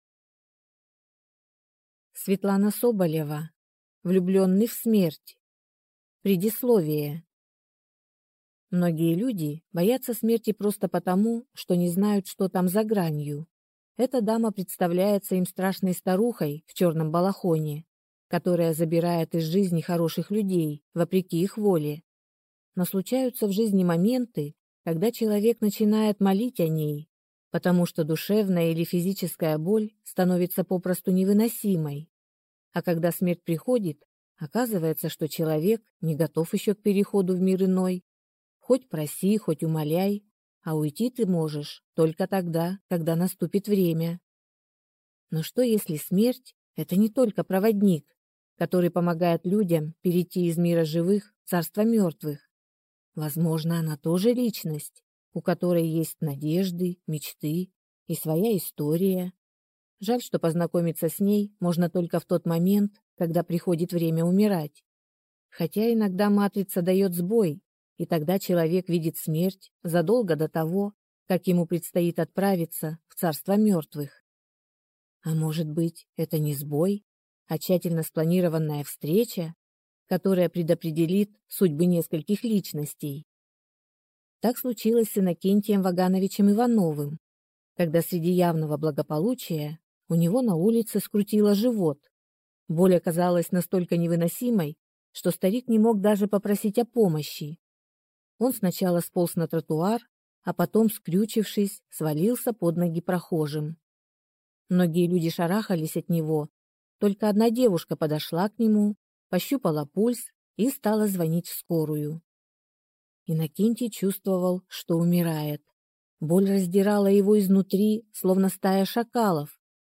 Аудиокнига Влюблённый в Смерть | Библиотека аудиокниг